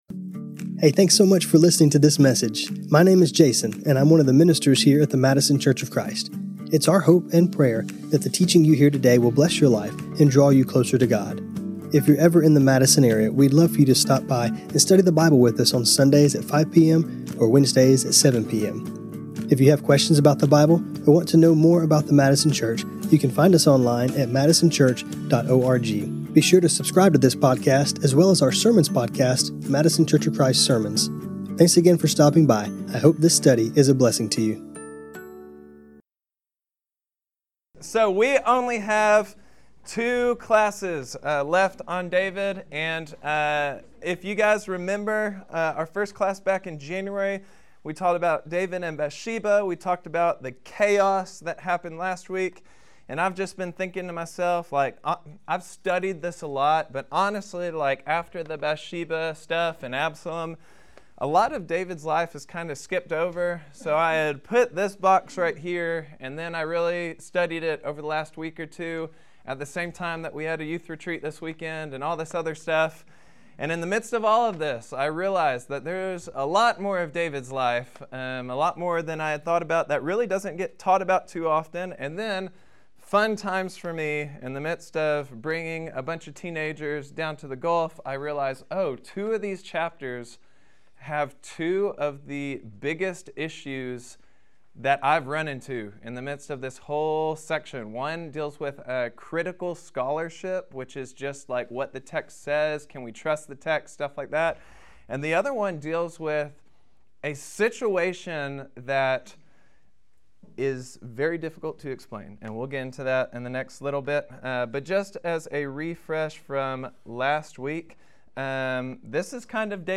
This class was recorded on Jan 21, 2026.